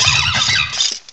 cry_not_carnivine.aif